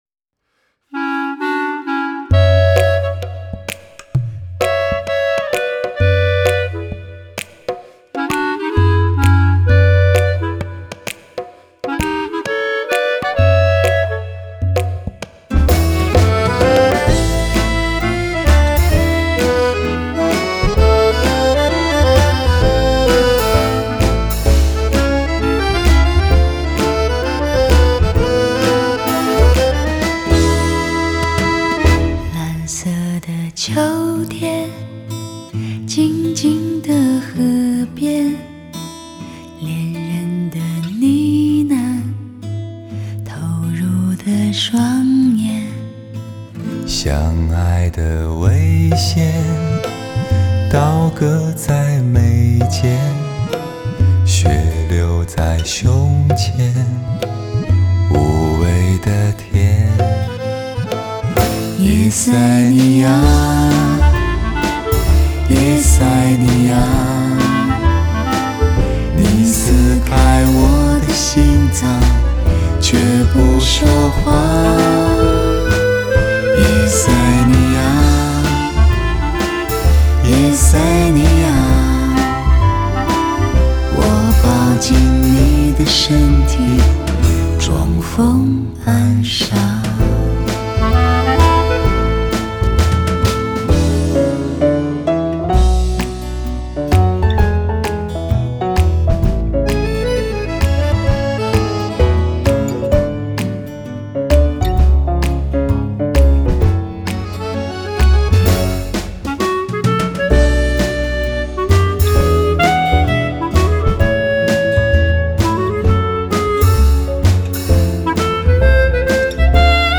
综观整张专辑，人声就像露珠一样晶莹剔透，乐器的伴奏有现场感，录音有层次感！